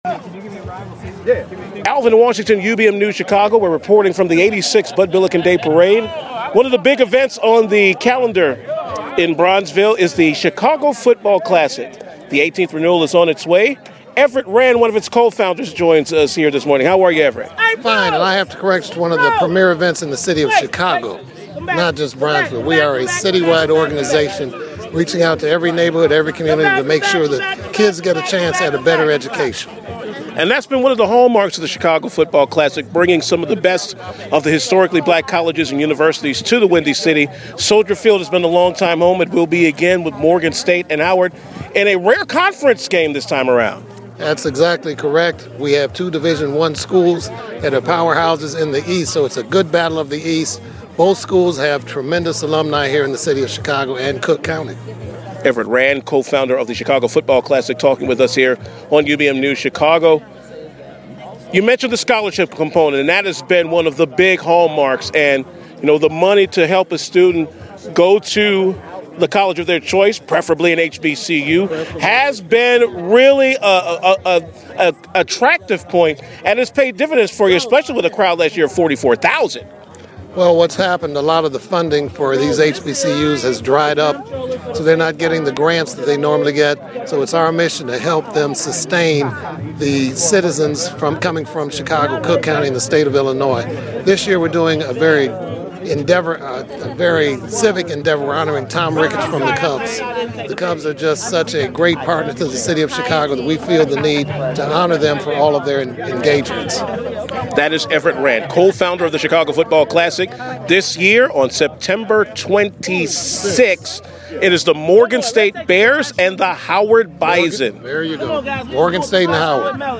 at the Bud Billiken Parade